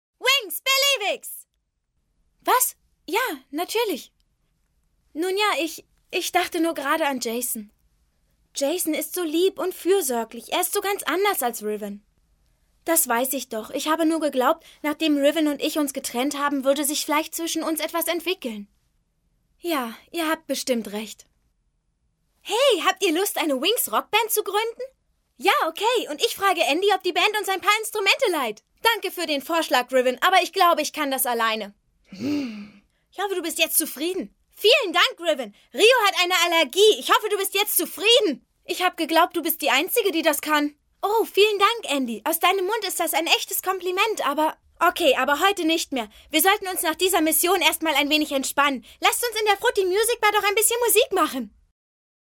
Sorry, Dein Browser unterstüzt kein HTML5 (Synchron) - Attack the Block Winx Club (Synchron)